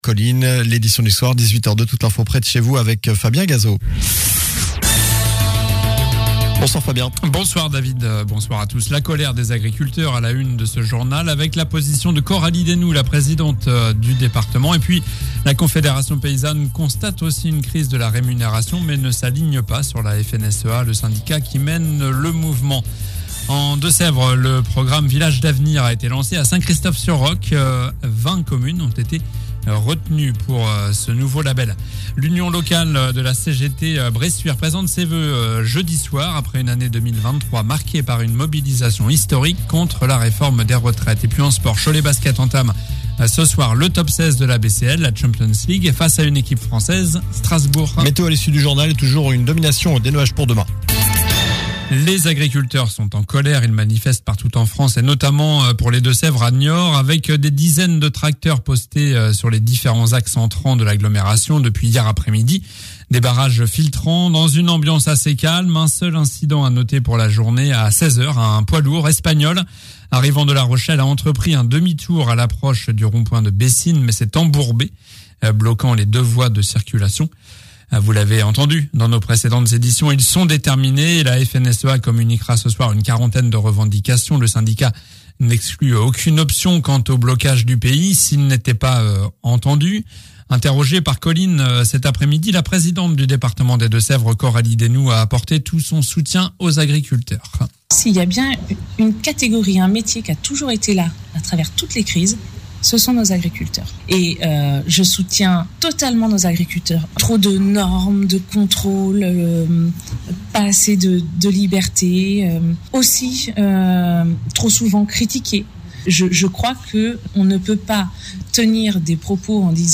Journal du mercredi 24 janvier (soir)